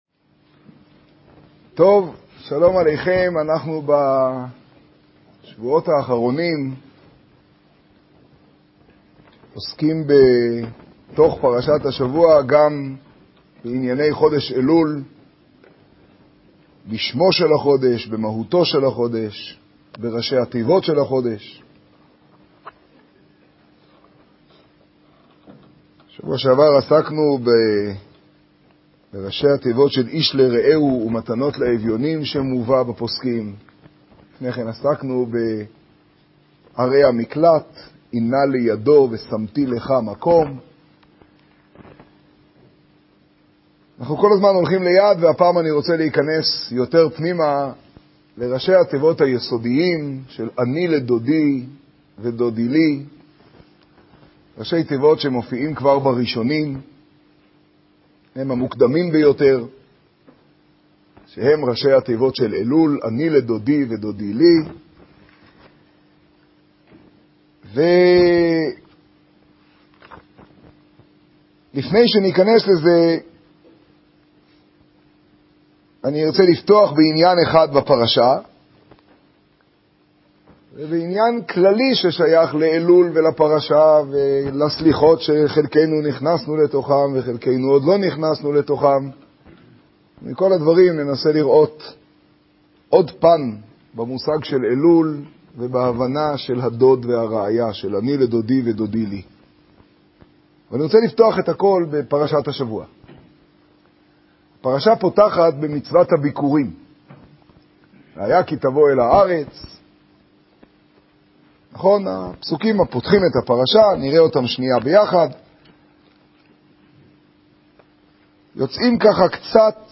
השיעור בירושלים, פרשת כי תבא תשעא.